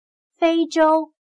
非洲/fēizhōu/África